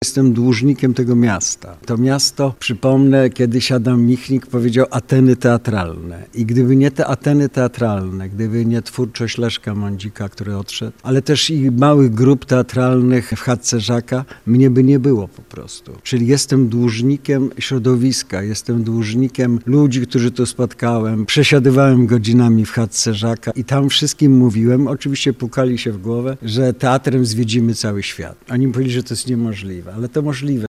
– Jestem mocno związany z Lublinem – mówił podczas dzisiejszej konferencji prasowej zapowiadającej wydarzenie